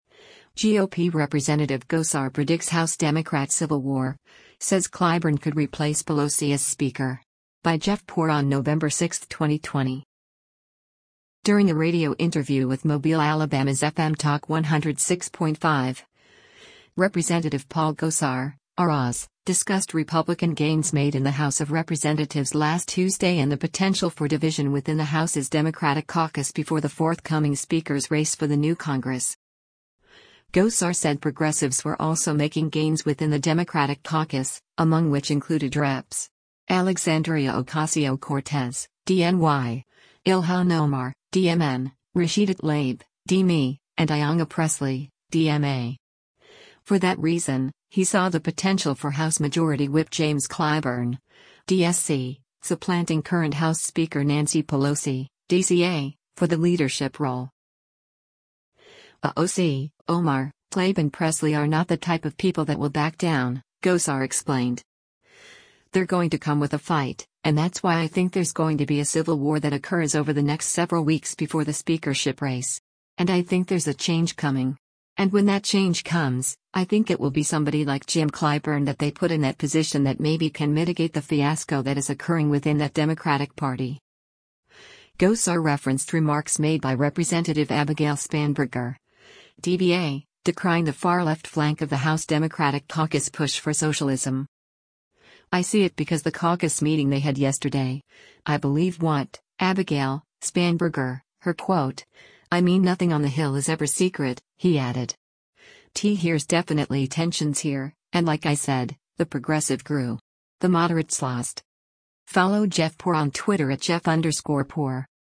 During a radio interview with Mobile, AL’s FM Talk 106.5, Rep. Paul Gosar (R-AZ) discussed Republican gains made in the House of Representatives last Tuesday and the potential for division within the House’s Democratic caucus before the forthcoming speaker’s race for the new Congress.